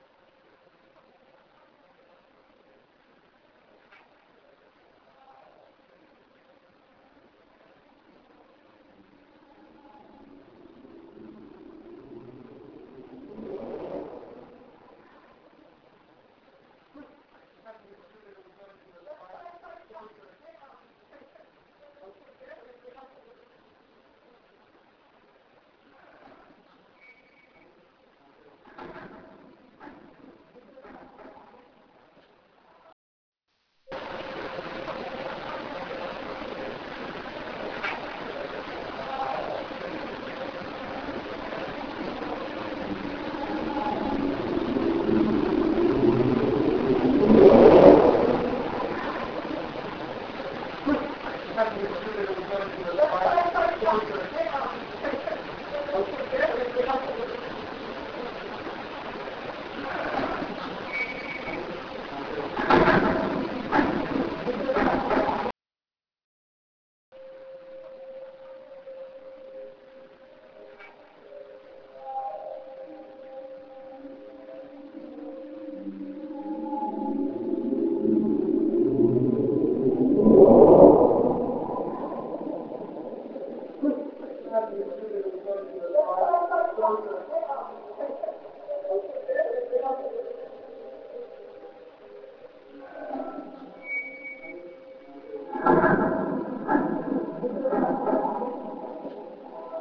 The original files are sent to IMPS in Wyoming for editing and cleaning using CoolEdit Pro 2.0 with hiss reduction (which causes odd effects in itself) and volume normalization.
Scary "Varoom" with voices and gate rattling - The voices and gate are due to proximity of the pool, they are not caused by the paranormal.
Original x1 Amplified x1  Cleaned x1
Scary_Varoom_with_Voices_and_Gate_Rattling_.wav